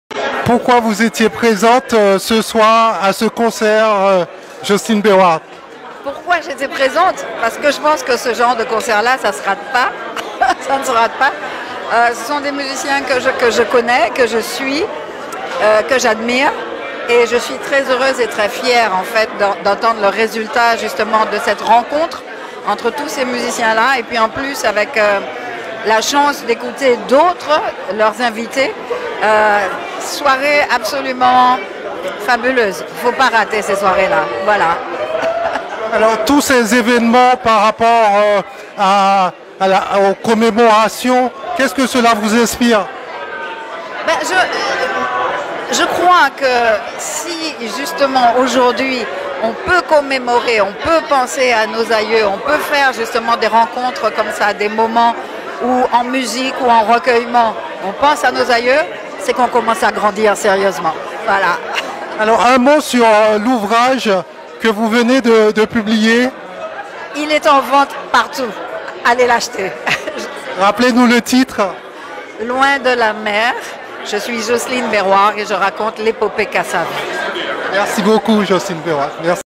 Jocelyne Beroard, publie "loin de l'amer" qui raconte l'épopée du groupe KASSAV - interview
A l'occasion de la fête de la musique ce 21 juin, Radio Mayouri Campus rend hommage à Jocelyne Beroard du groupe KASSAV' qui vient de publier "Loin de L'amer" qui raconte l'épopée de ce groupe mythique. Nous l'avons rencontrée au "Big in Jazz collective" pour la Nuit de la Caraïbe, le mardi 17 mai à ALHAMBRA Paris.